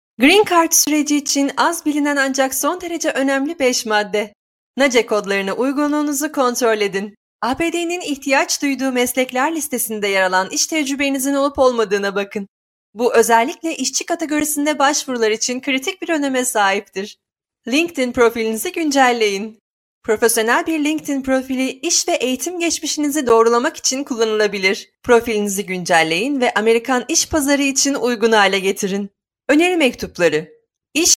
土耳其语样音试听下载